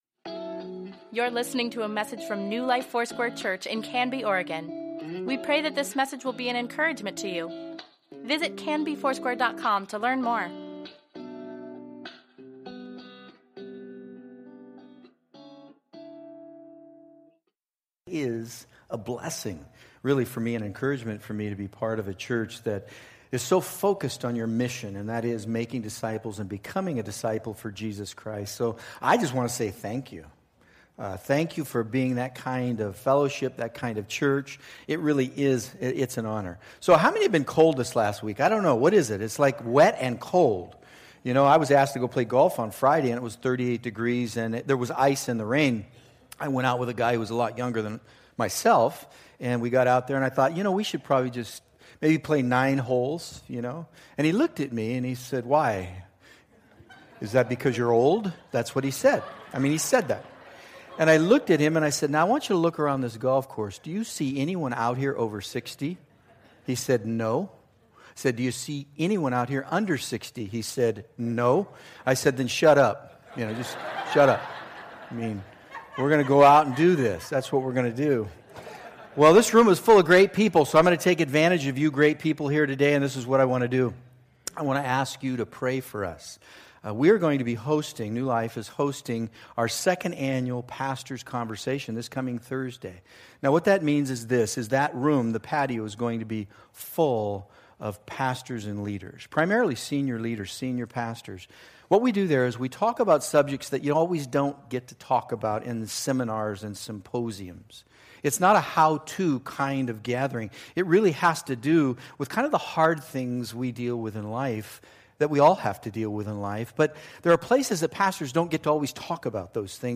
Weekly Email Water Baptism Prayer Events Sermons Give Care for Carus IMPACT: Part 7 February 26, 2017